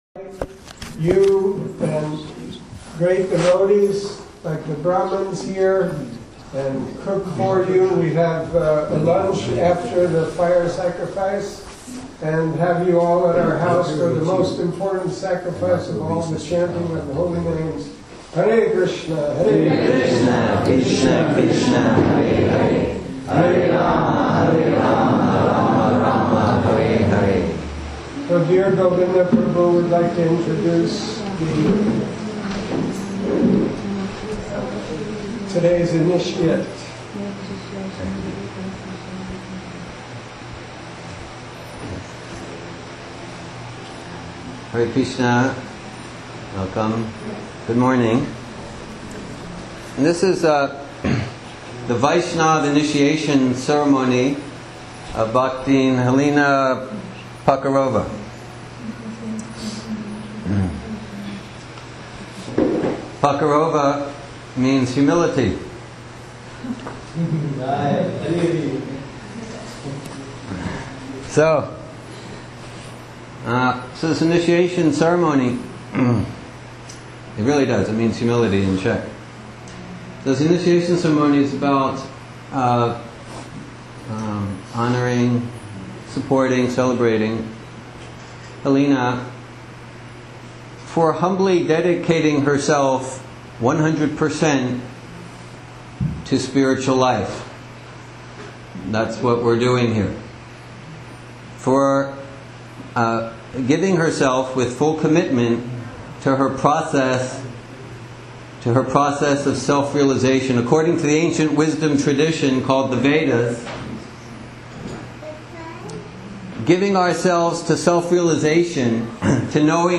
initiation event audio recording March 20 2016